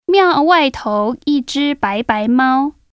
Record yourself reading it, then listen to how the speakers pronounce it.